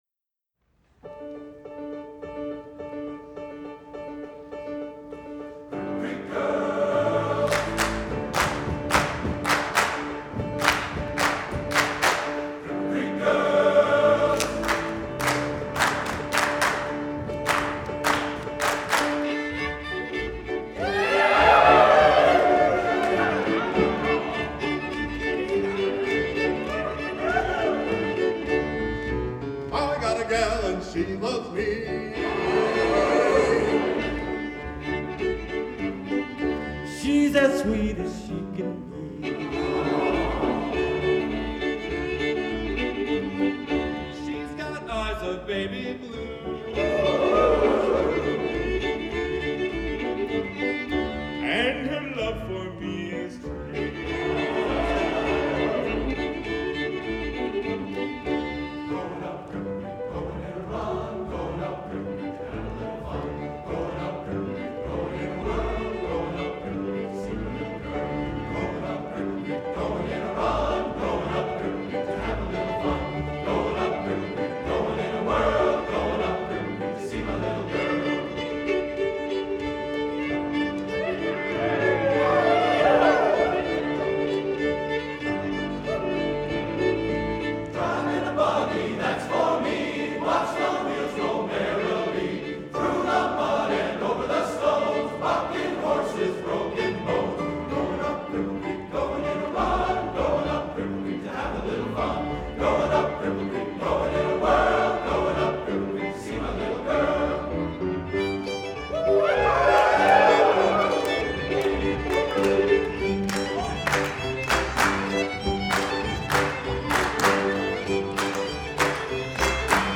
Spring 2018 — Minnesota Valley Men's Chorale